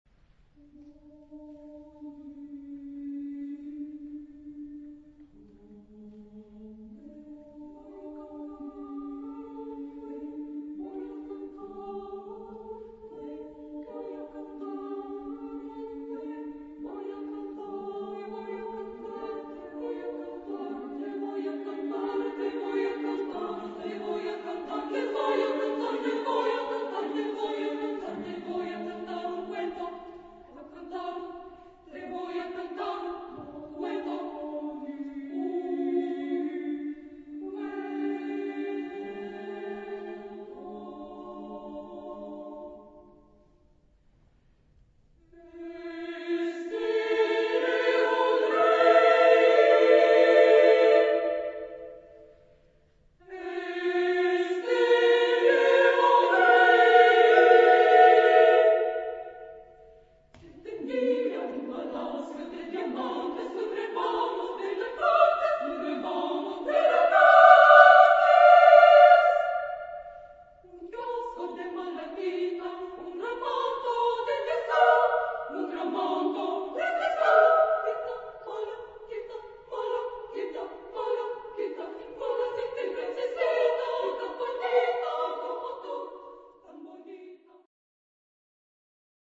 Epoque: 20th century
Genre-Style-Form: Partsong
Type of Choir: SSMC  (4 women voices )